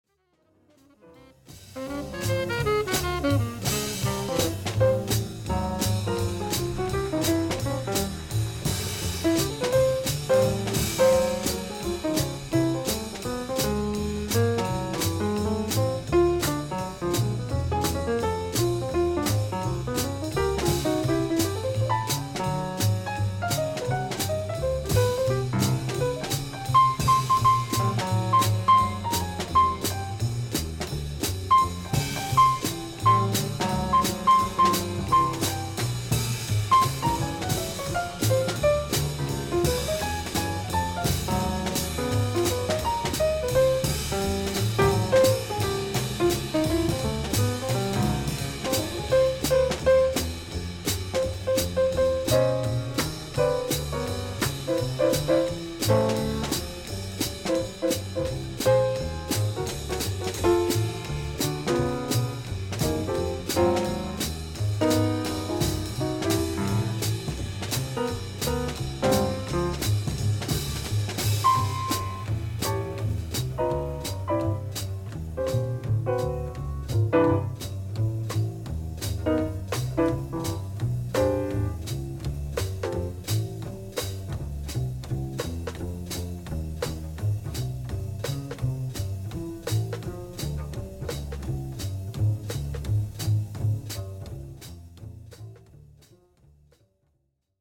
live gigs of the quartet